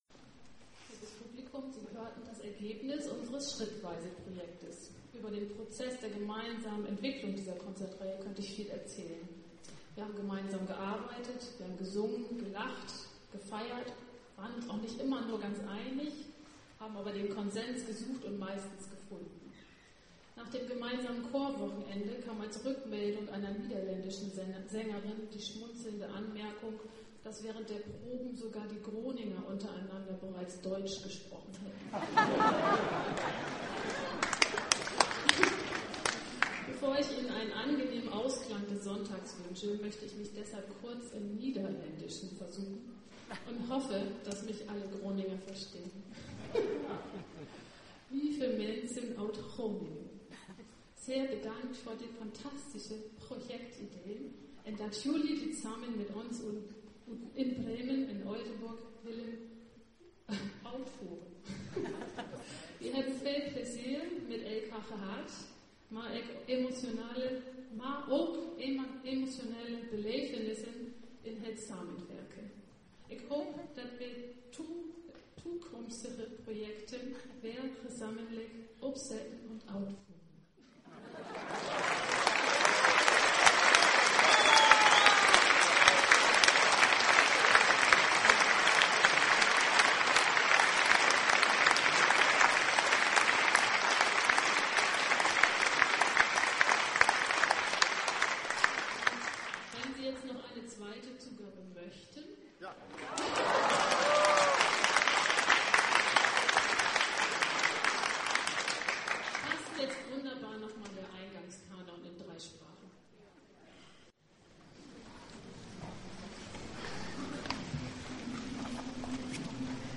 Dankwoord en canon afsluitend concert Bremen 6 mei (mono, 2MB)
6 mei 2012 Bremen Obere Rathaushalle 17:00
Slotwoord en Leben einzeln - Concert Bremen - mono.mp3